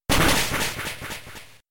hit-super-effective.mp3